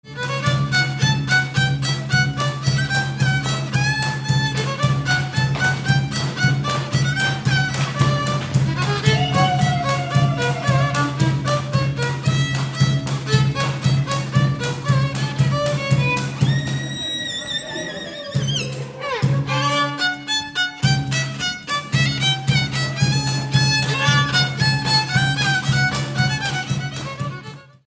Dallampélda: Hangszeres felvétel
Erdély - Csík vm. - Csíkszentdomokos
hegedű
ütőgardon
Műfaj: Párnástánc
Stílus: 7. Régies kisambitusú dallamok